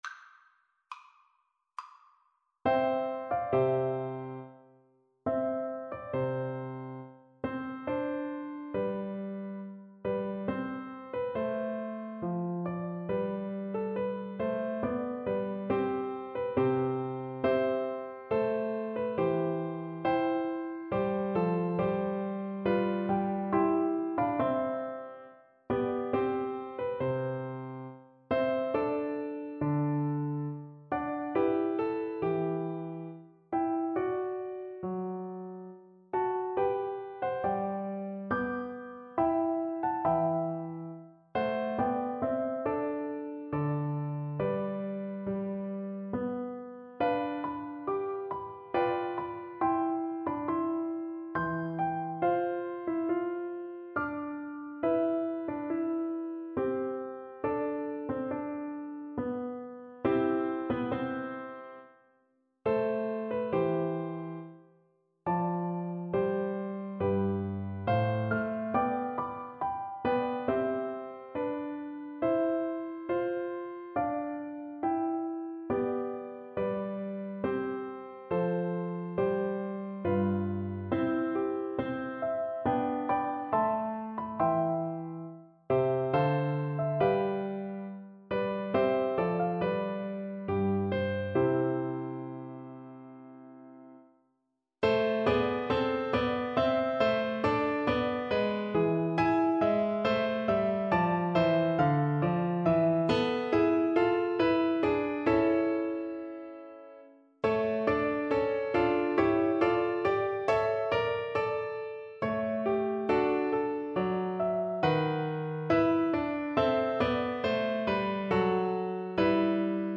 Play (or use space bar on your keyboard) Pause Music Playalong - Piano Accompaniment Playalong Band Accompaniment not yet available transpose reset tempo print settings full screen
Flute
C major (Sounding Pitch) (View more C major Music for Flute )
Largo =69
3/4 (View more 3/4 Music)
Classical (View more Classical Flute Music)